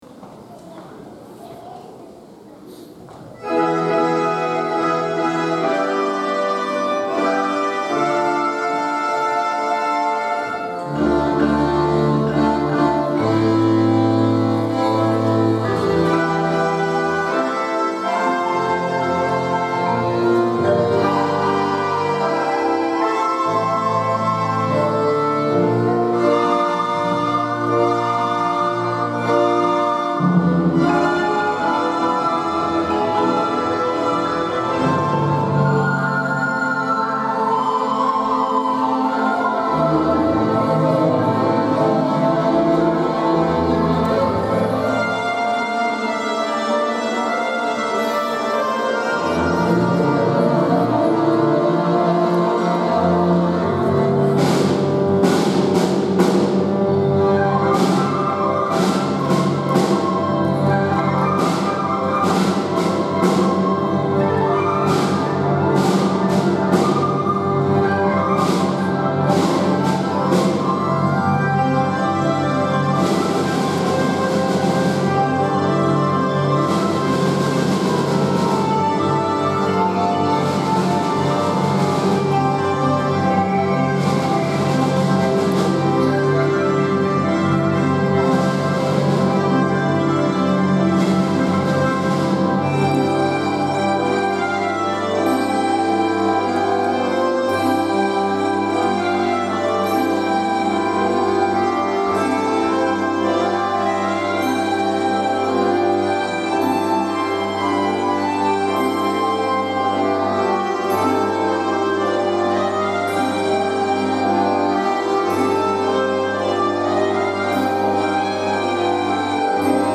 今日は第１４回大空ふれあいコンサートです！
５・６年生（高学年チーム）　序曲「
曲の始まりは厳かな雰囲気の正教会の音楽から始まります。
1曲の中でテンポが速くなったり遅くなったりしてとても難しい曲ですが、子どもたち一人ひとりが100％の力を出し切って演奏しました♪
華やかなメロディーが会場中に響き渡り、大きな拍手に包まれました！